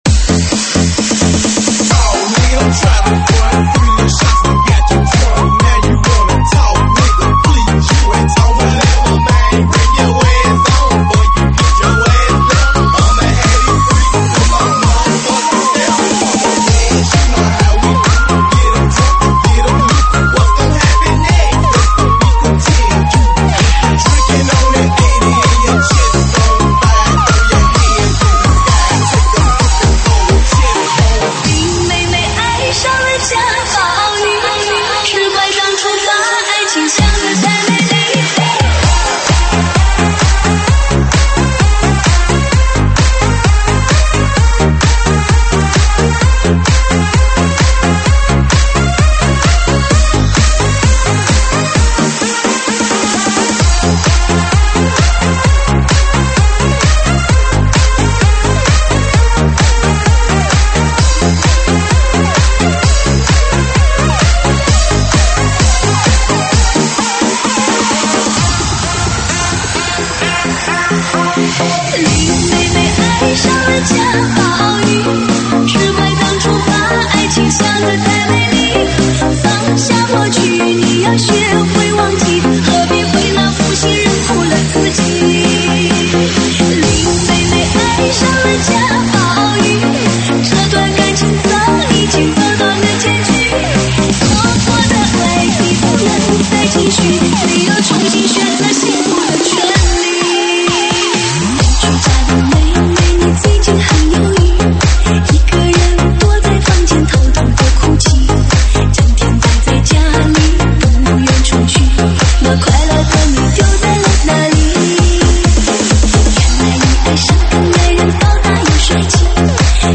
收录于(现场串烧)